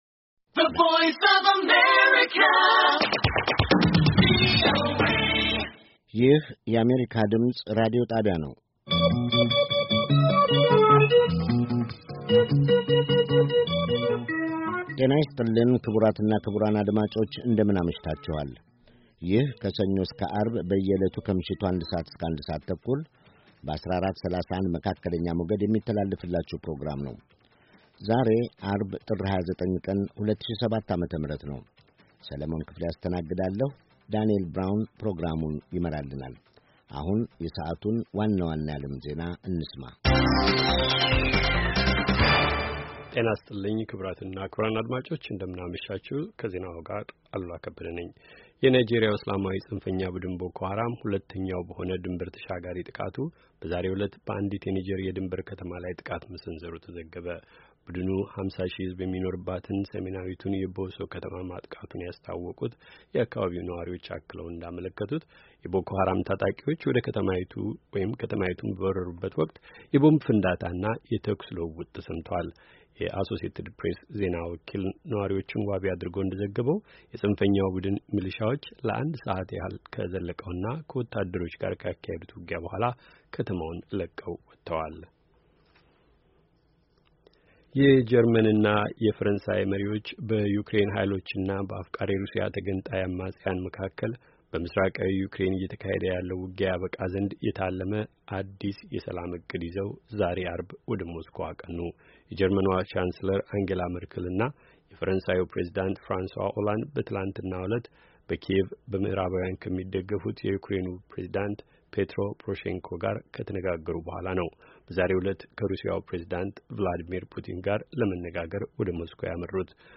Early edition of Amharic News